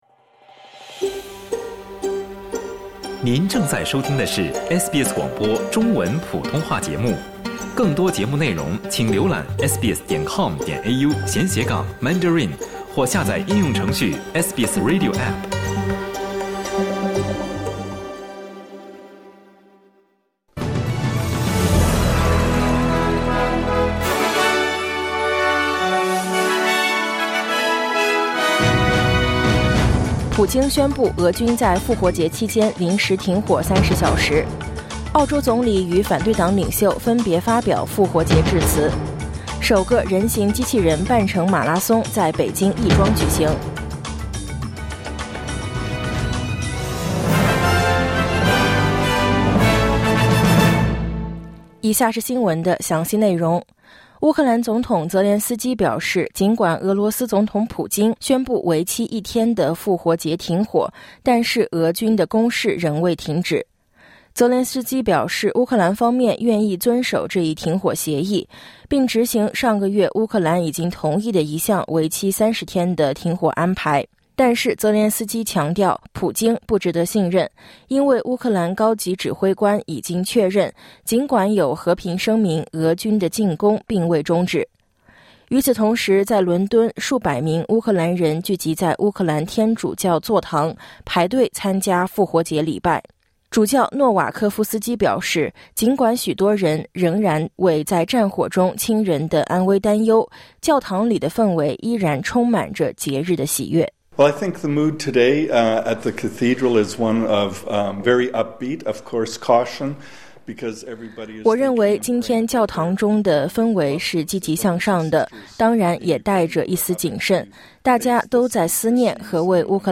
SBS早新闻（2025年4月20日）